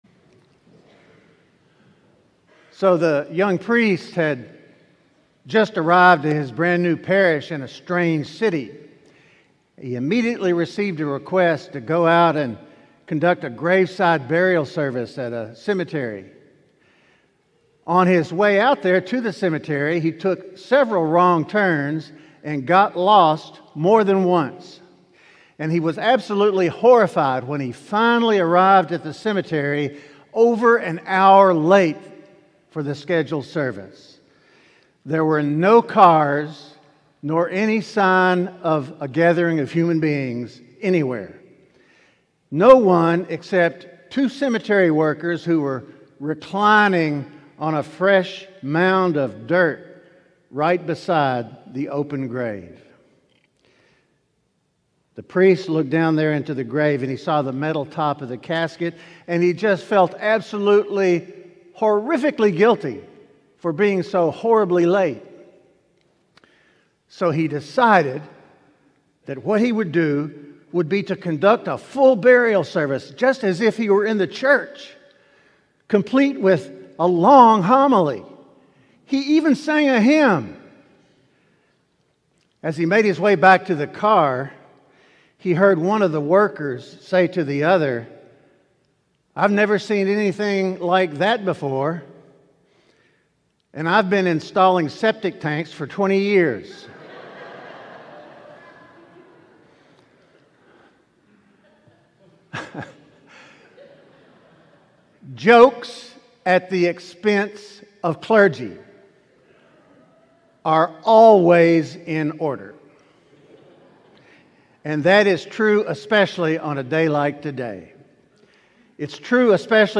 Sermon: It's On You